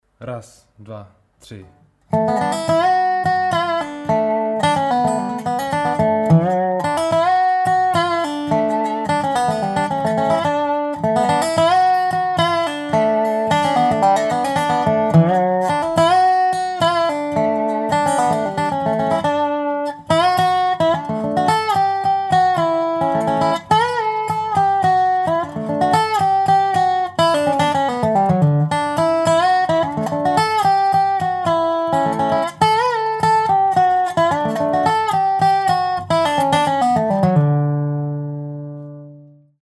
v D Dur🎵 zdroj - video